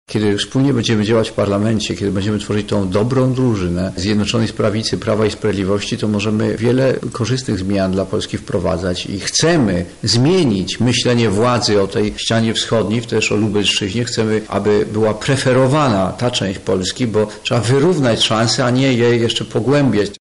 Dzisiaj trudno uwierzyć w bezinteresowność władzy – mówił Zbigniew Ziobro.